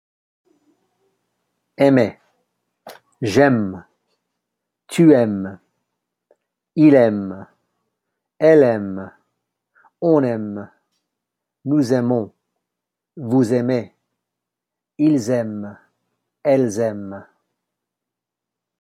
(They actually all sound like the letter M, even though they are spelled differently!) Are the final consonants of aimons and aimez pronounced? (No!)